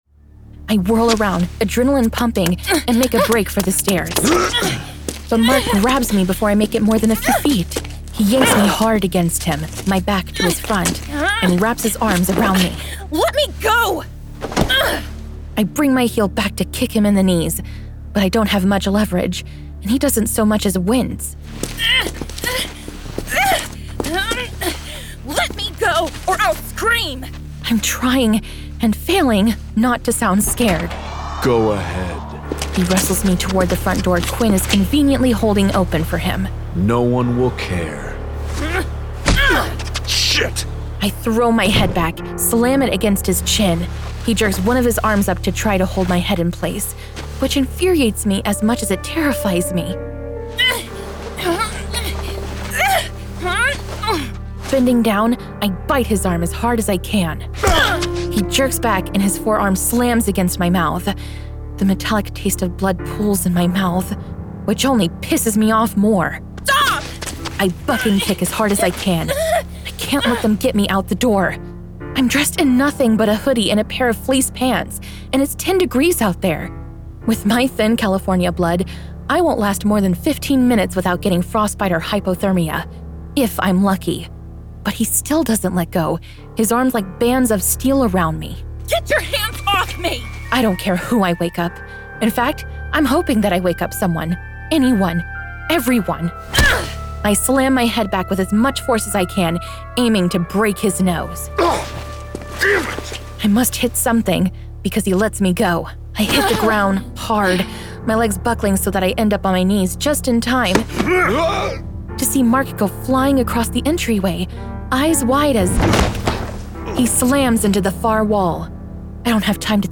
Full Cast. Cinematic Music. Sound Effects.
Adapted from the novel and produced with a full cast of actors, immersive sound effects and cinematic music.